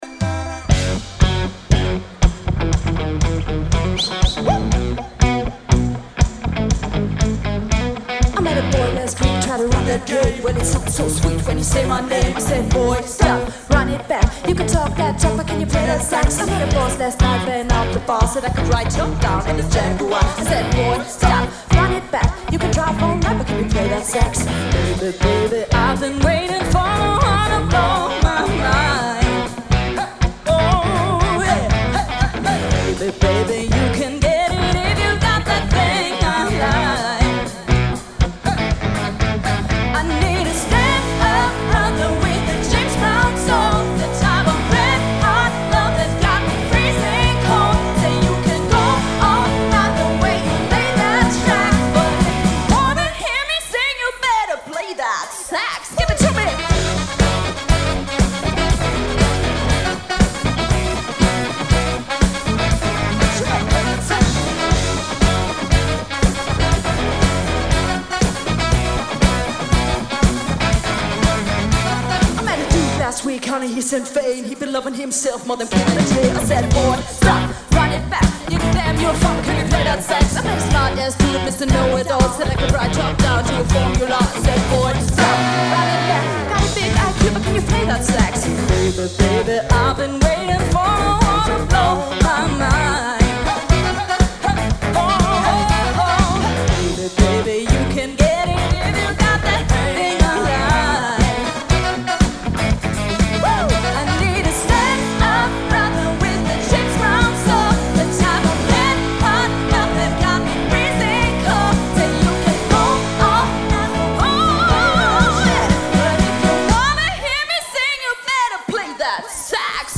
premium live music entertainment